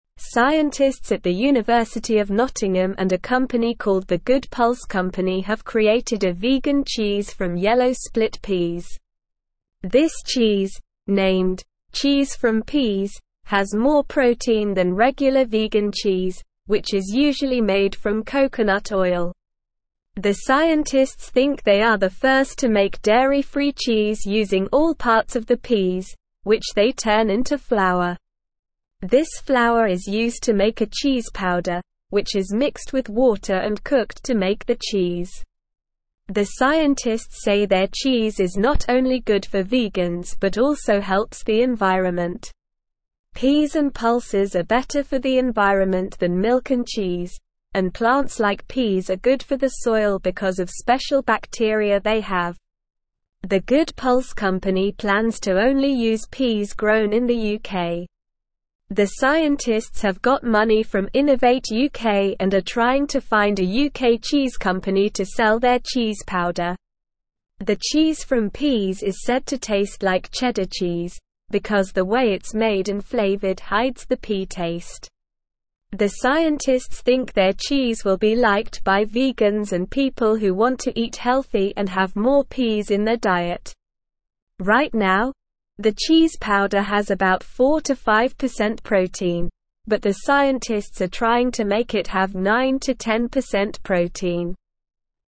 English-Newsroom-Lower-Intermediate-SLOW-Reading-New-Cheese-Made-from-Peas-Tasty-and-Healthy.mp3